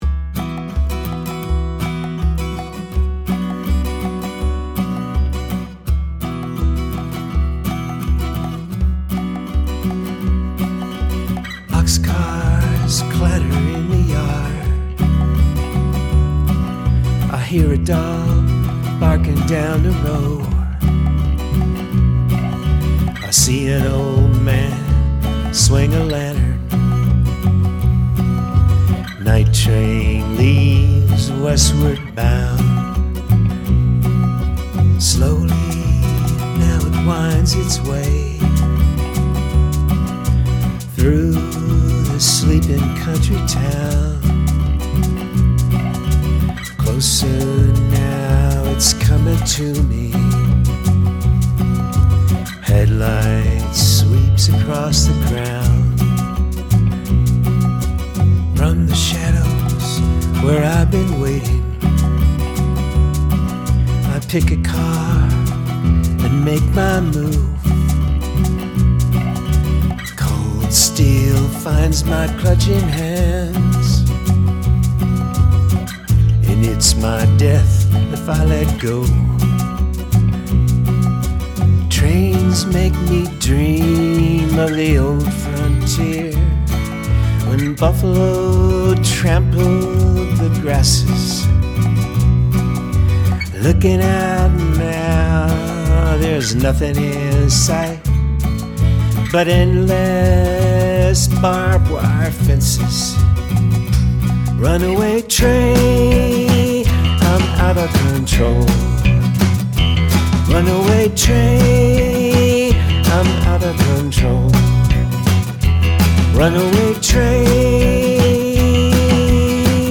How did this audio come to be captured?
But I wanted to share these lo-fi recordings of works in progress with our community, because who knows if I’ll ever have time to polish them for mass consumption?